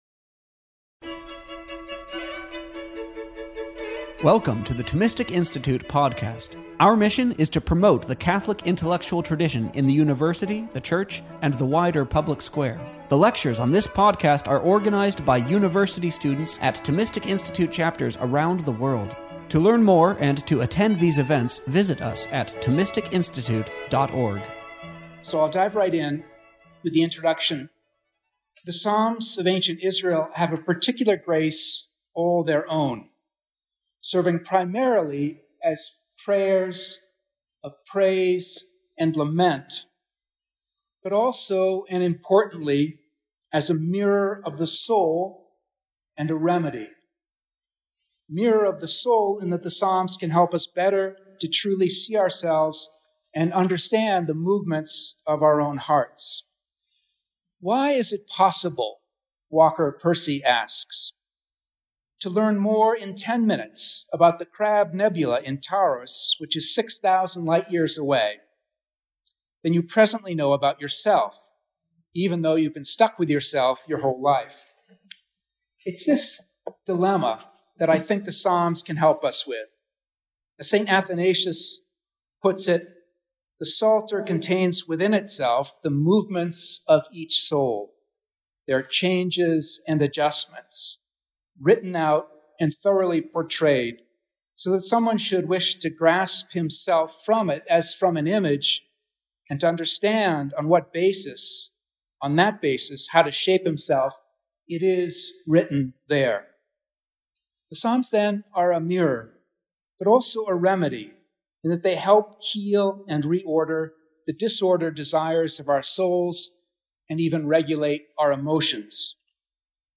This lecture was given on March 10th, 2025, at Dominican House of Studies.